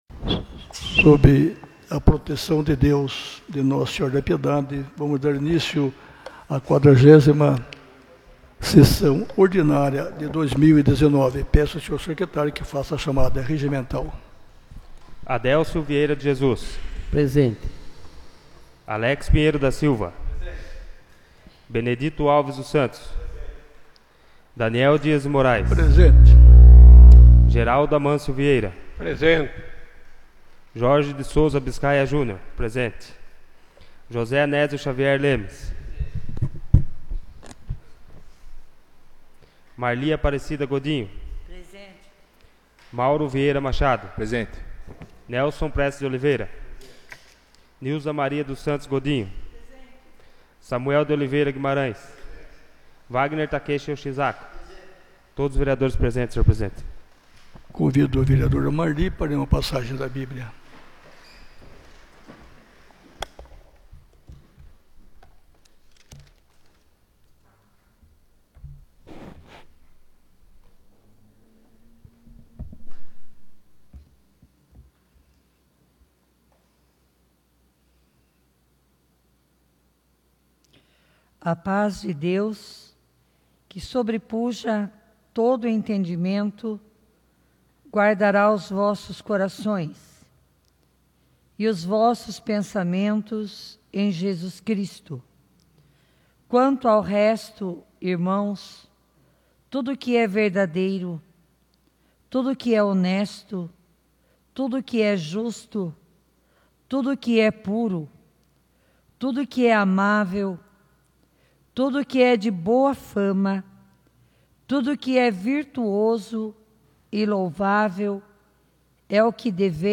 40ª Sessão Ordinária de 2019 — Câmara Municipal de Piedade